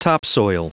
Prononciation du mot topsoil en anglais (fichier audio)